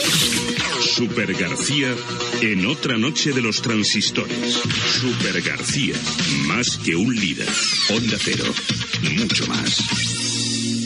Promoció del programa
Esportiu
FM